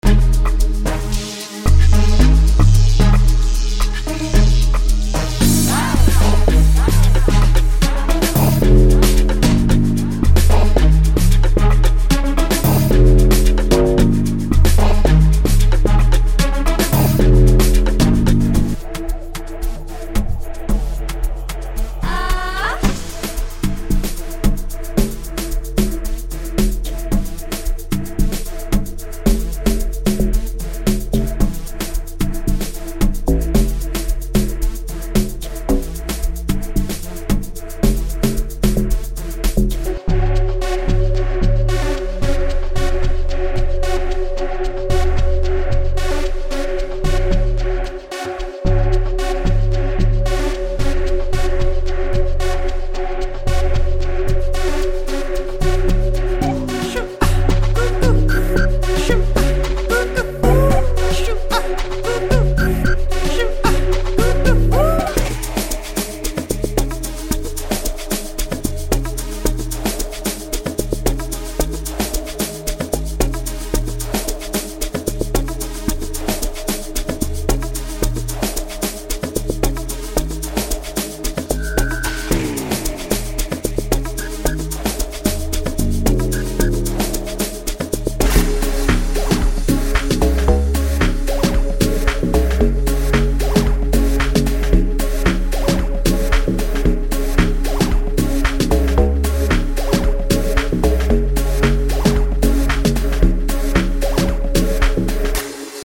• 38 Melodic Loops
• 19 Bass Loops
• 85 Drum Loops